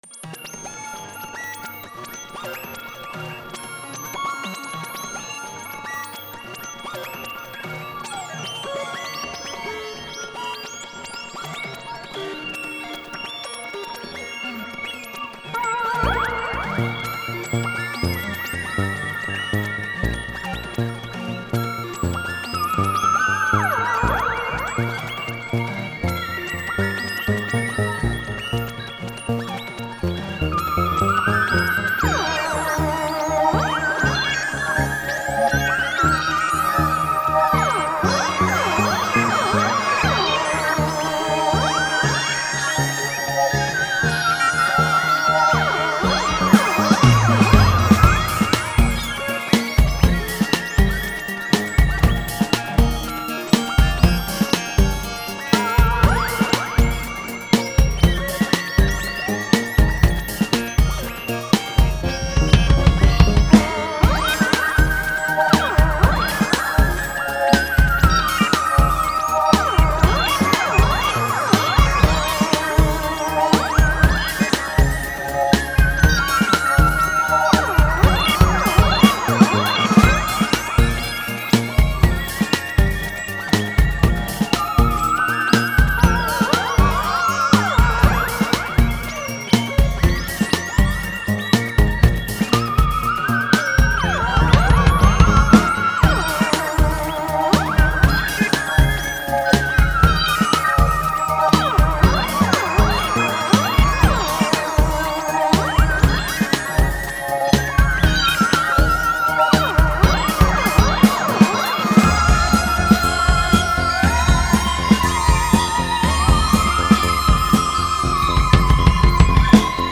Electronic music inspired by space